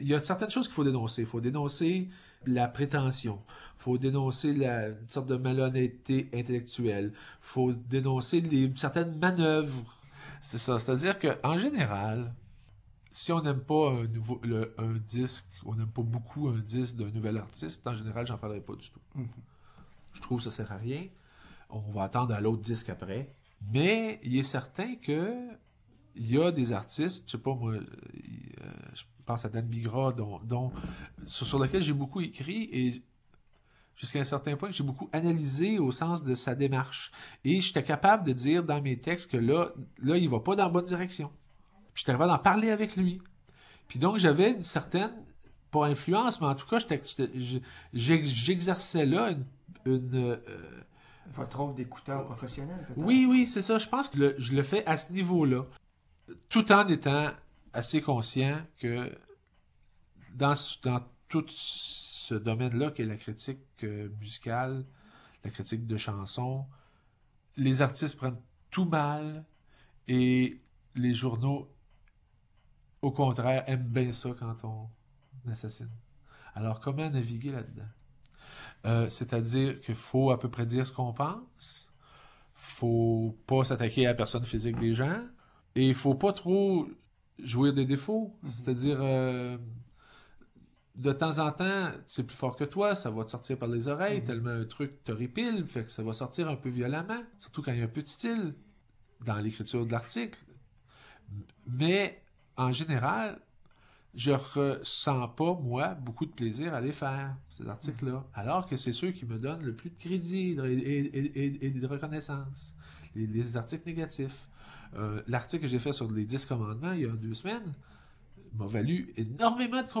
Extraits sonores des invités